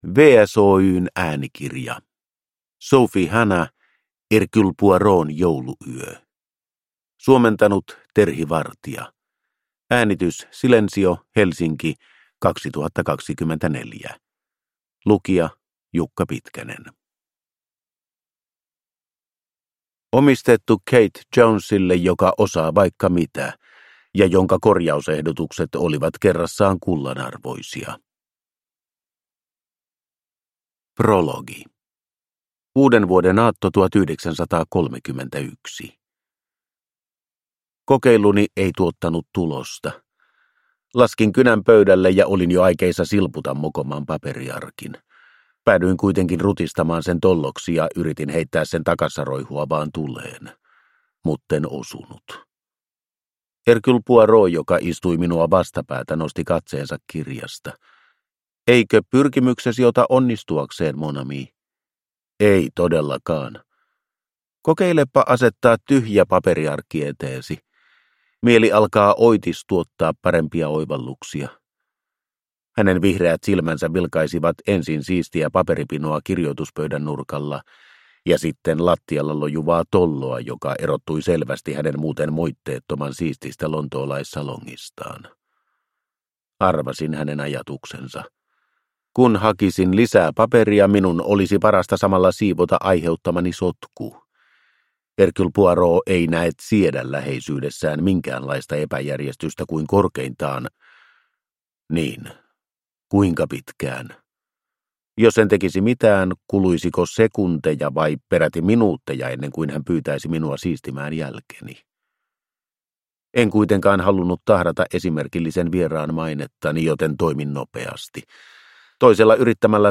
Hercule Poirotin jouluyö – Ljudbok